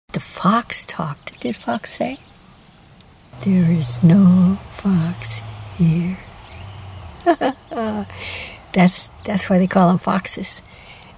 Before it said it like this [speaking very low, with flat emotional affect]; There’s no Fo … No! … There’s no … There’s … It’s hard for me to speak that low …